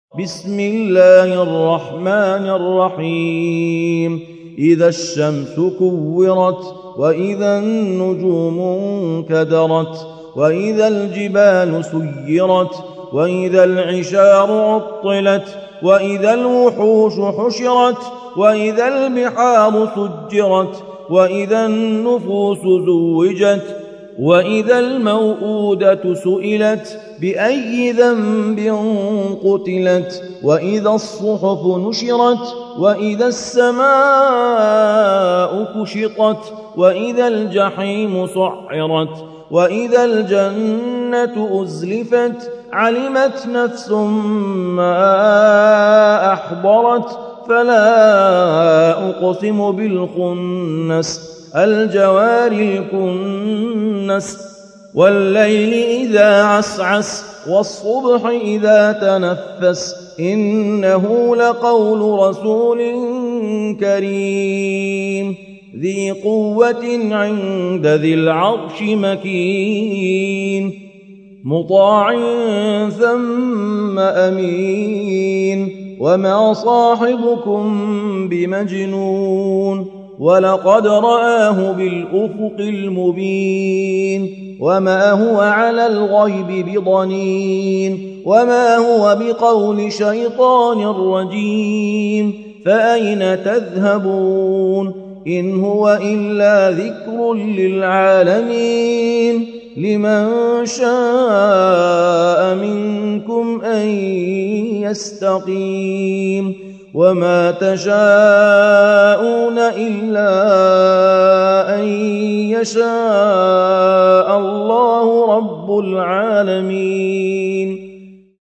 التصنيف: تلاوات مرتلة